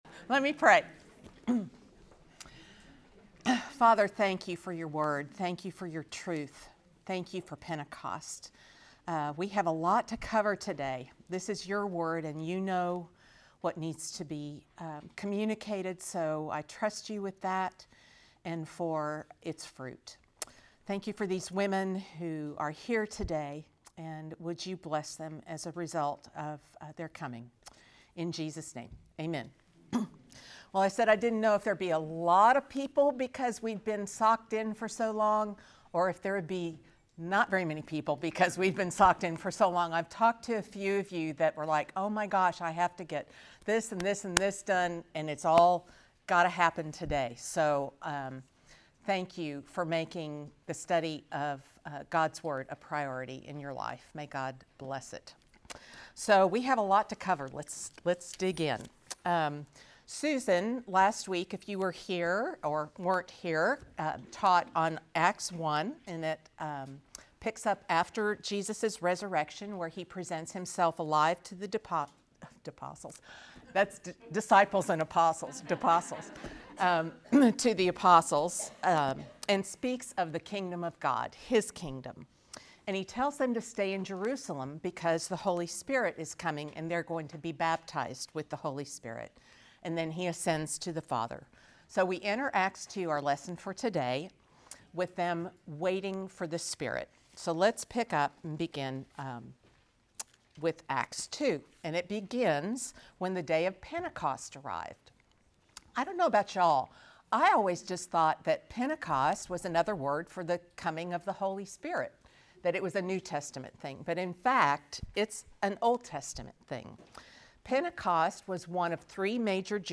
Service Type: Lectures